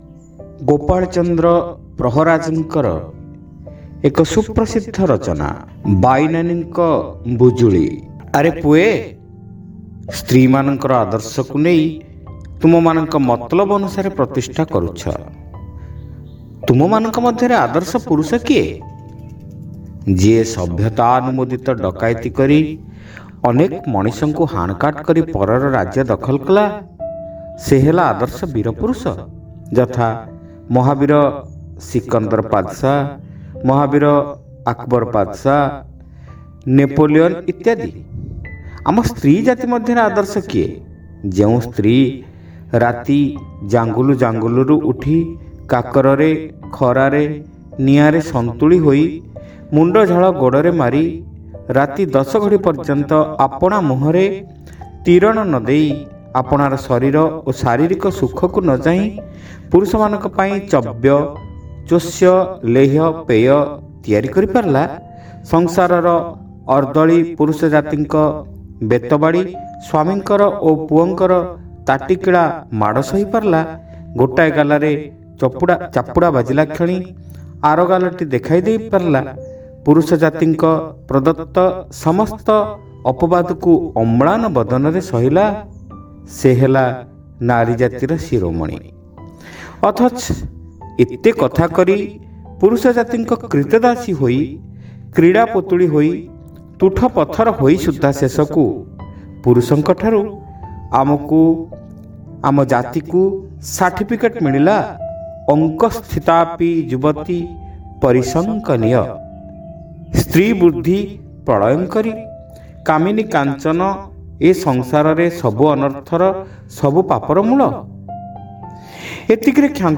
ଶ୍ରାବ୍ୟ ଗଳ୍ପ : ବାଇନାନୀଙ୍କ ବୁଜୁଳି